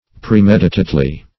premeditately - definition of premeditately - synonyms, pronunciation, spelling from Free Dictionary Search Result for " premeditately" : The Collaborative International Dictionary of English v.0.48: Premeditately \Pre*med"i*tate*ly\, adv. With premeditation.